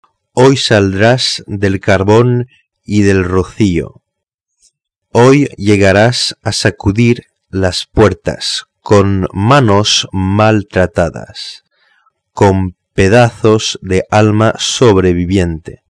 POUR ENTENDRE LA PRONONCIATION EN ESPAGNOL